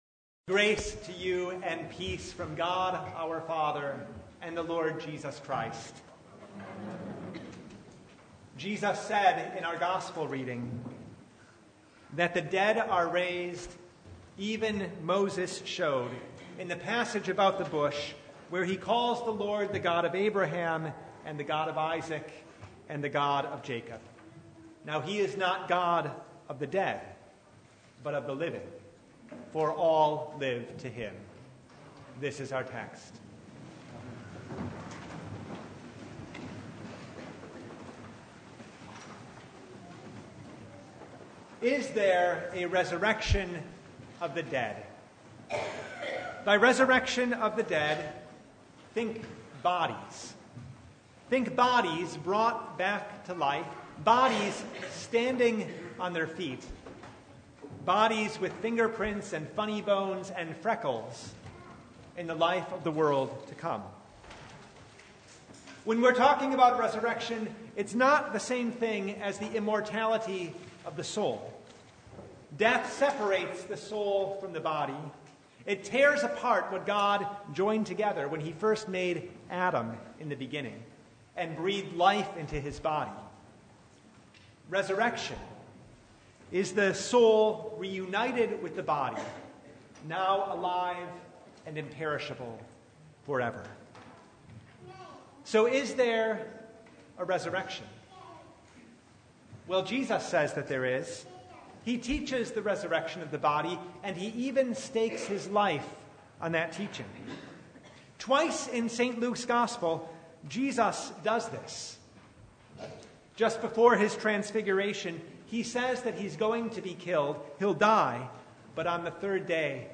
Passage: Luke 20:27-40 Service Type: Sunday Bible Text
Sermon Only